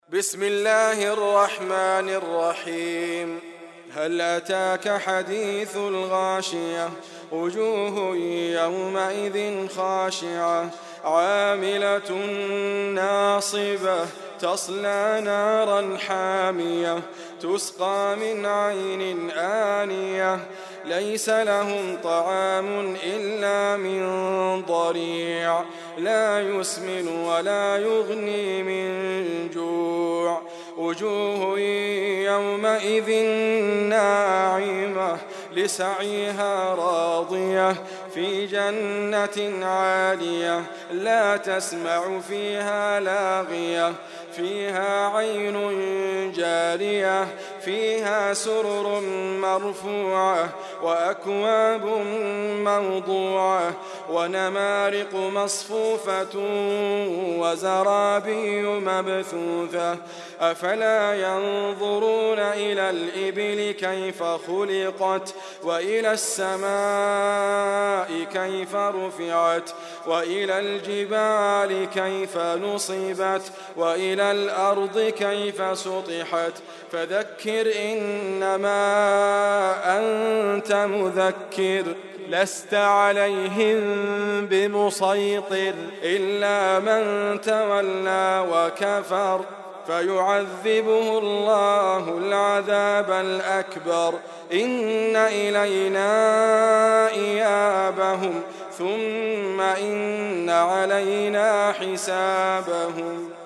مكتبة محروم الاسلامية - اناشيد , محاضرات , قران كريم mp3 , فيديو , كليبات , محاضرات , ديبيات اسلامية , - الغاشية - ادريس ابكر - القران الكريم